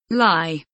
lie kelimesinin anlamı, resimli anlatımı ve sesli okunuşu